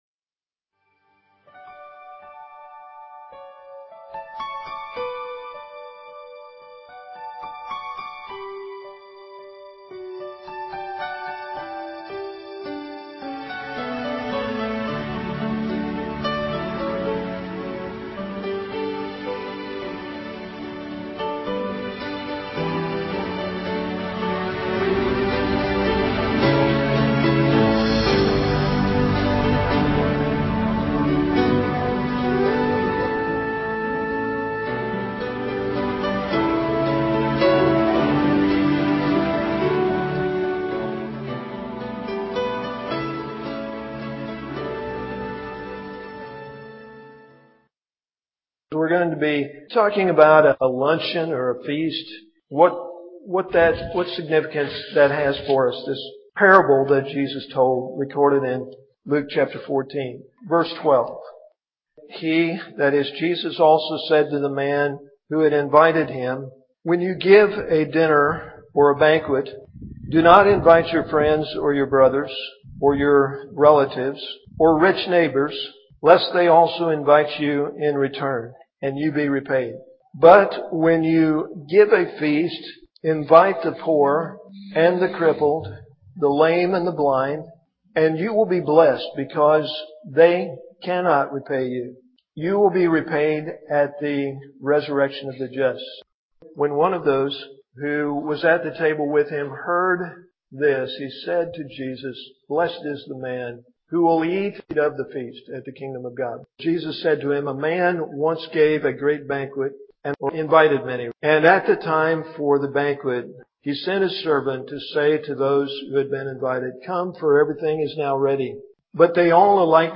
at Ewa Beach Baptist Church. Musical Intro/Outro: "How Beautiful."